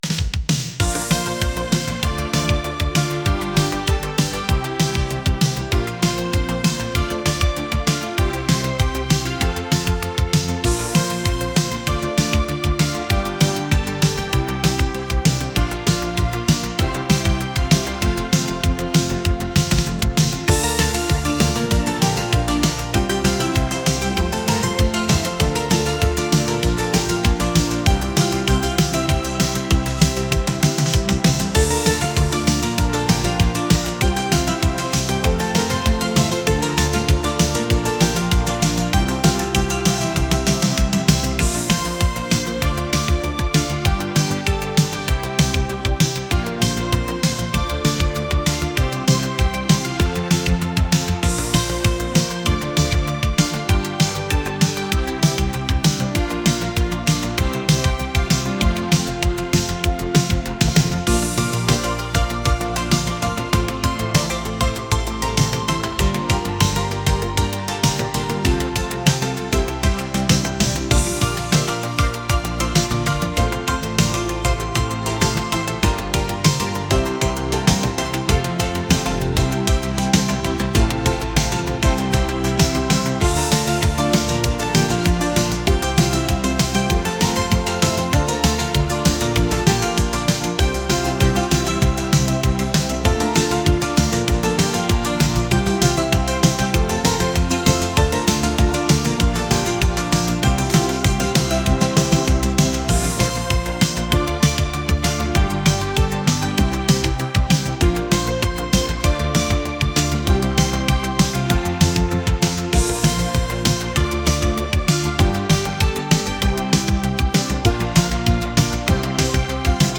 pop | retro | electronic